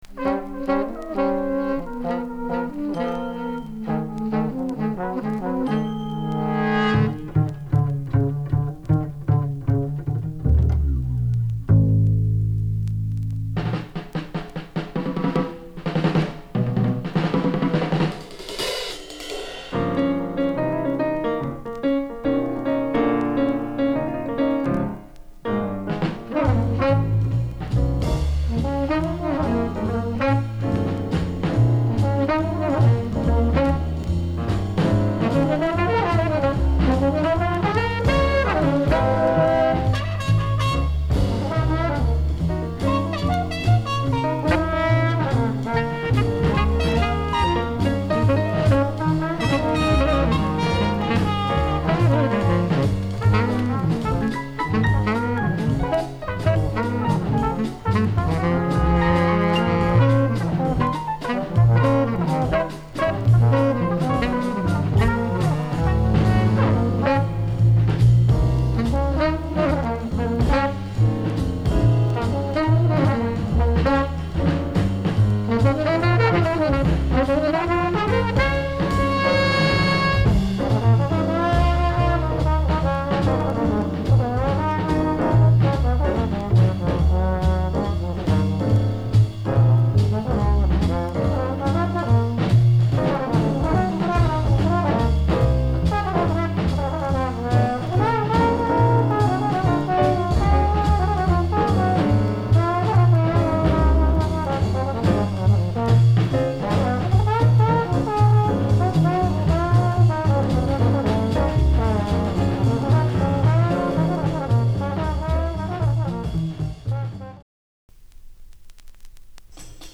discription:Stereo両溝 Stereo ニッパー犬隠しシール貼りあり
Format：LP